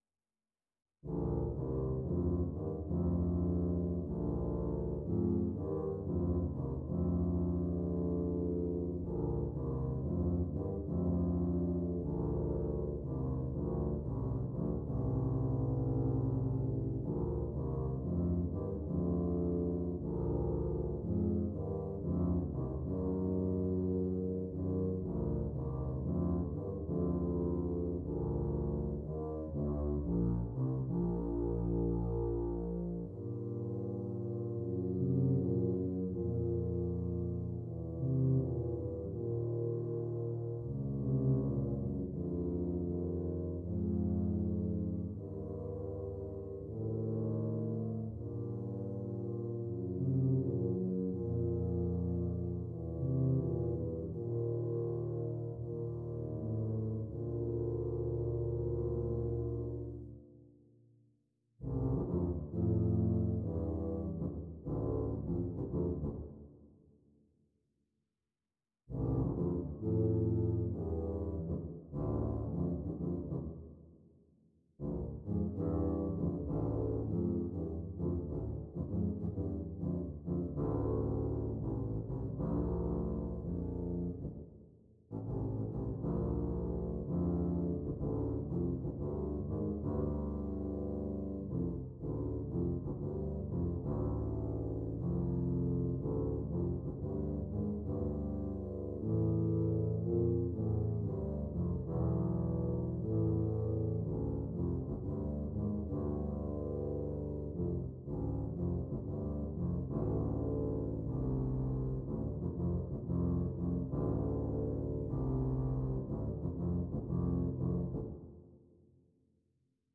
2 Tubas
A varied assortment of styles is offered for your merriment.